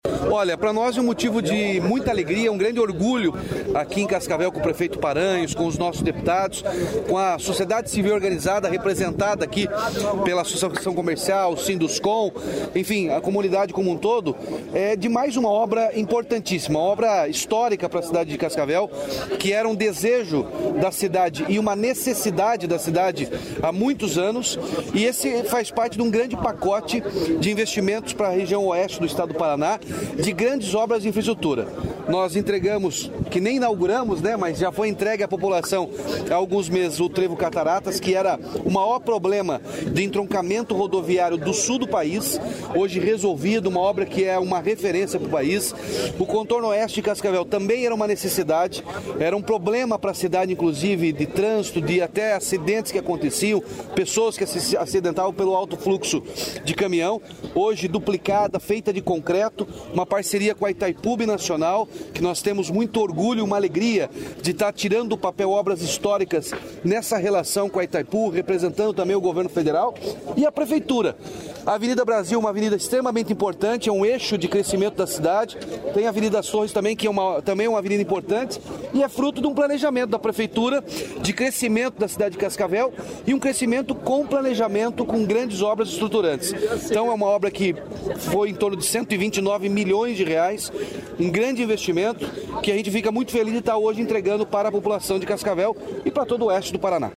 Sonora do governador Ratinho Junior sobre as obras da região de Cascavel